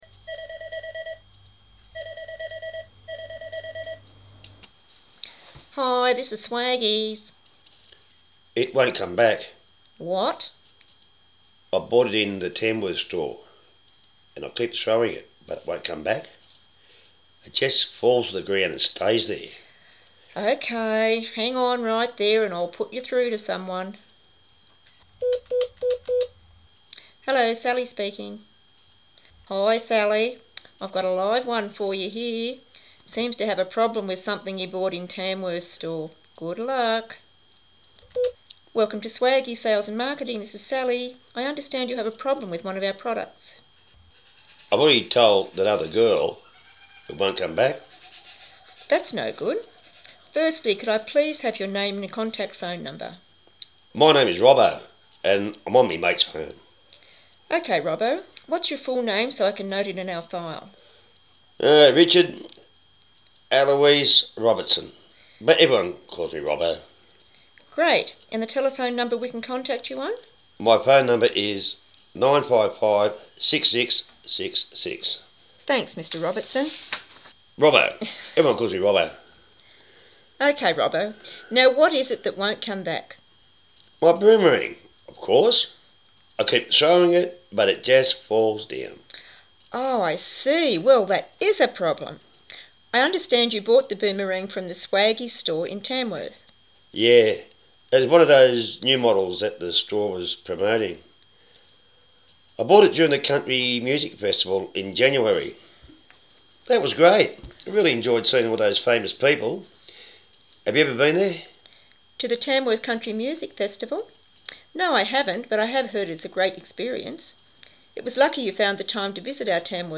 audio file (text version attached) is a record of a telephone conversation that took place between the staff of Swaggies Head Office and a customer. Listen to the recording then complete tasks 1 - 3.